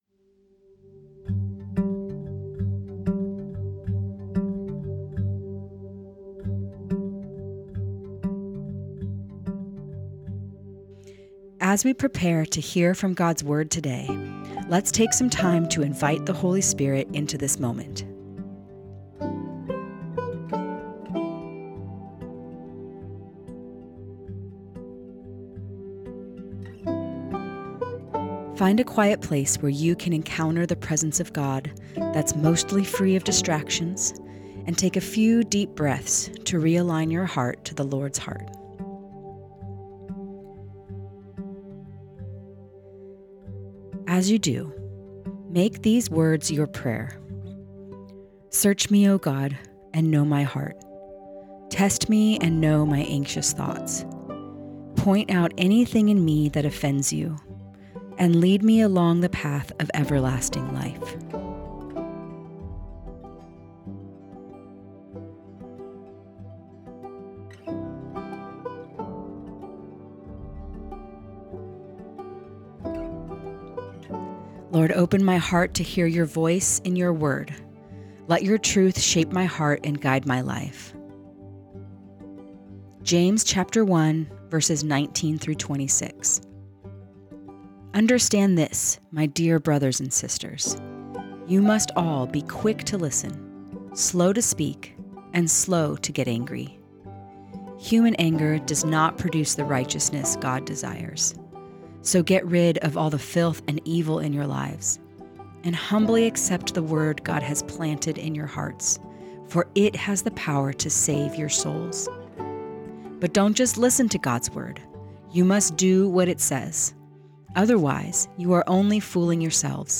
Guided Listening Practice Prepare As we prepare to hear from God’s Word today, let’s take a few moments to invite the Holy Spirit into this moment.